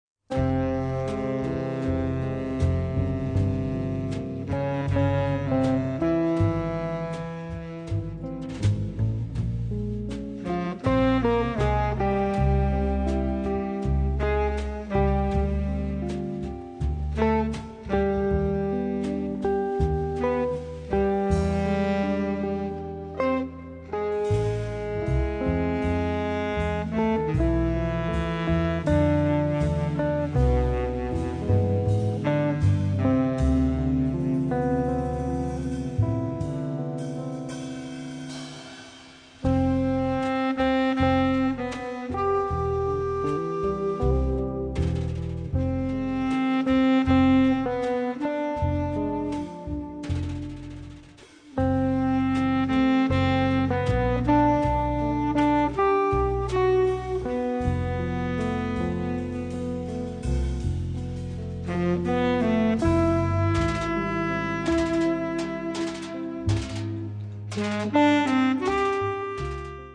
Chitarra
Sax Baritono
Contrabbasso e Basso Elettrico
Batteria